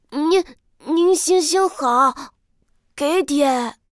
c01_6卖艺小孩A_1.ogg